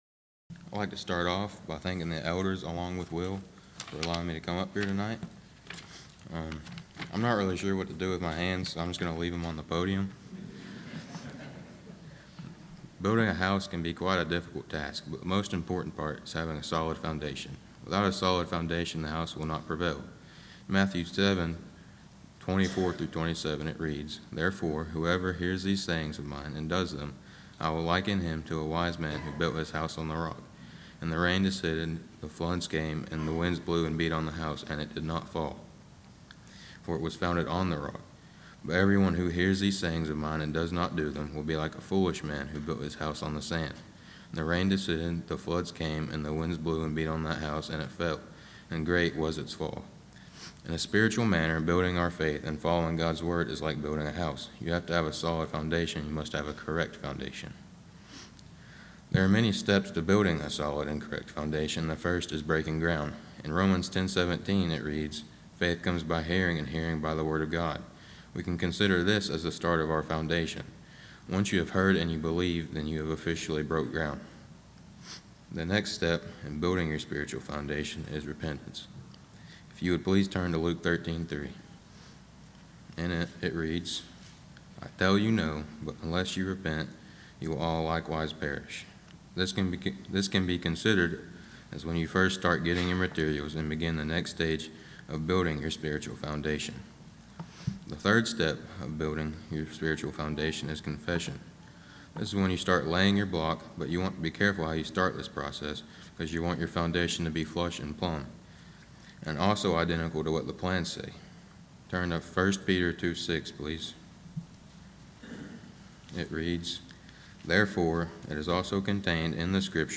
Young Men’s Service